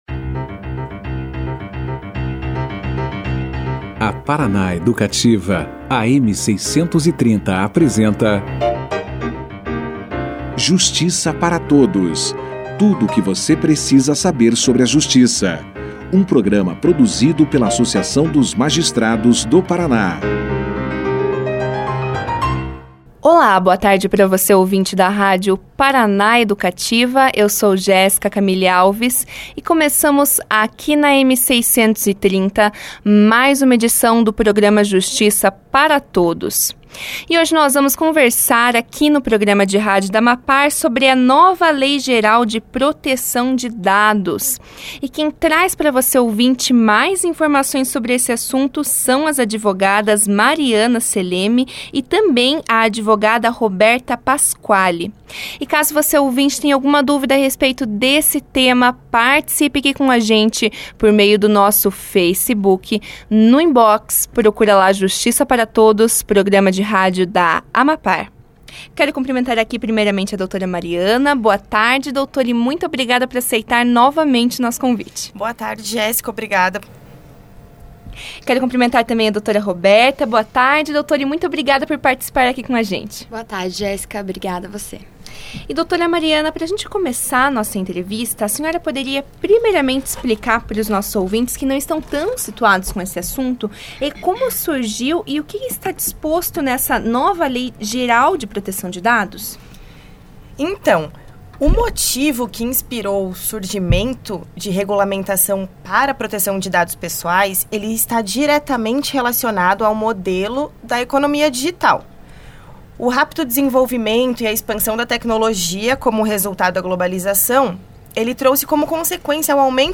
Questões como no que consistem os dados pessoais, os principais objetivos da nova legislação e as possíveis punições em caso de descumprimento da lei, foram esclarecidas pelas convidadas no início da conversa. Durante a entrevista, as convidadas também apontaram os principais direitos dos usuários.